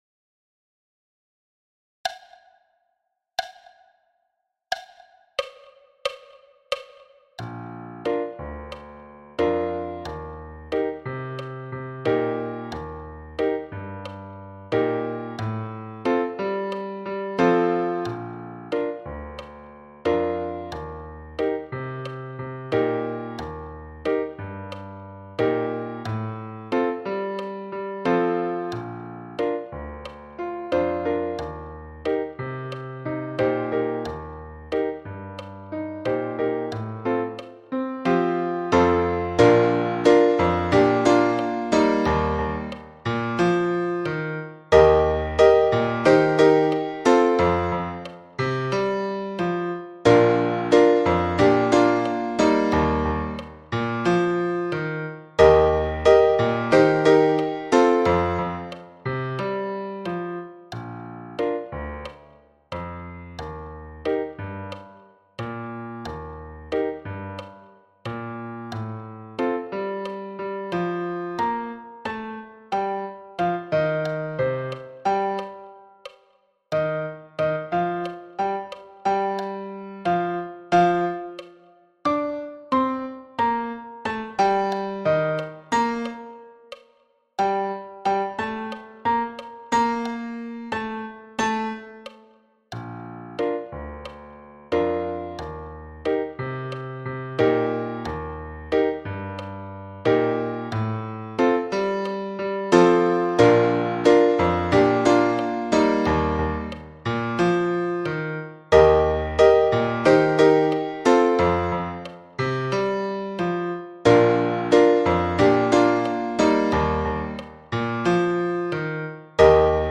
Rudimental rhumba – piano à 90 bpm
Rudimental-rhumba-piano-a-90-bpm.mp3